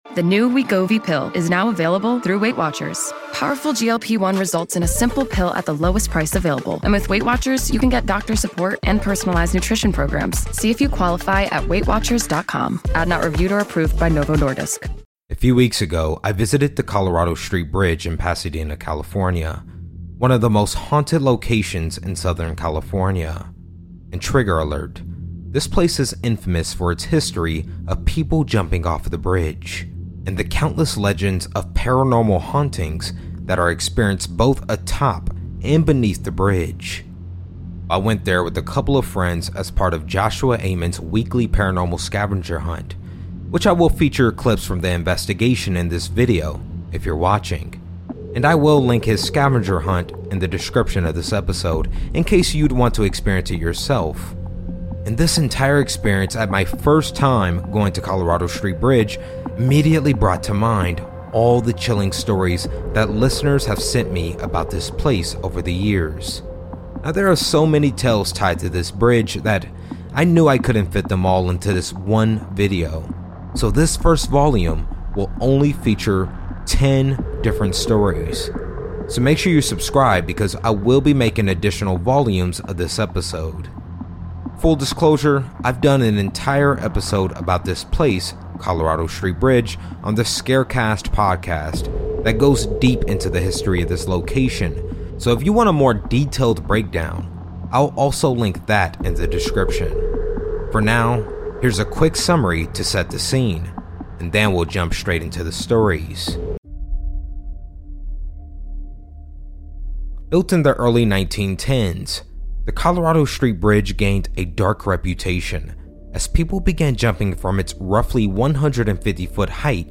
it blends unsettling true stories, paranormal encounters, and mysterious passings with immersive sound design and atmospheric storytelling to make every episode feel like a late-night campfire tale you can’t turn off.